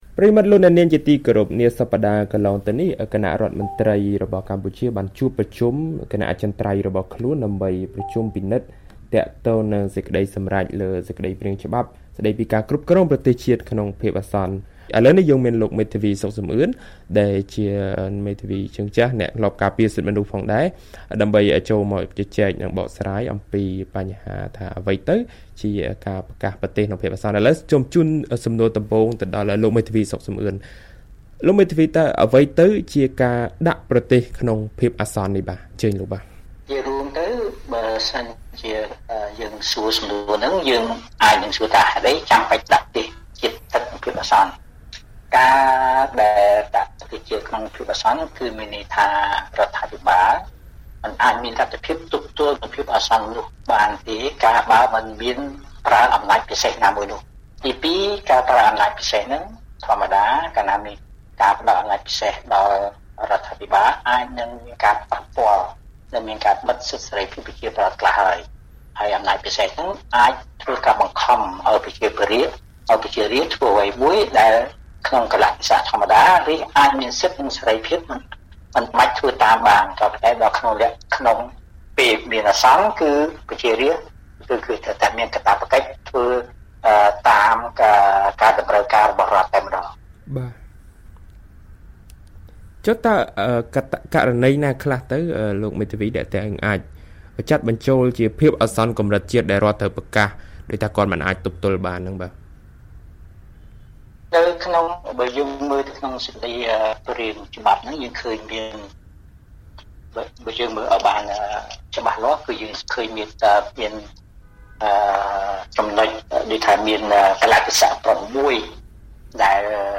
បទសម្ភាសន៍ VOA៖ អ្វីទៅជា«ការប្រកាសដាក់ប្រទេសក្នុងភាពអាសន្ន»?